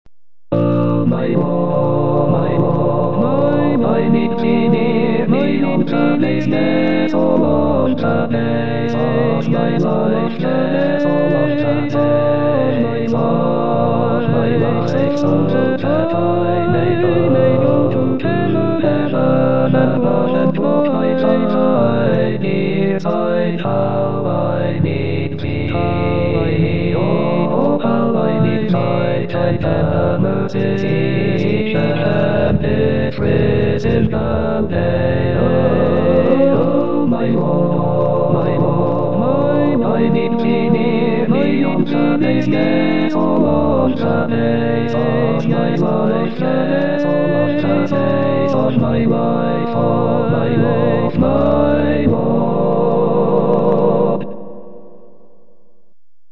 Language: English Instruments: A cappella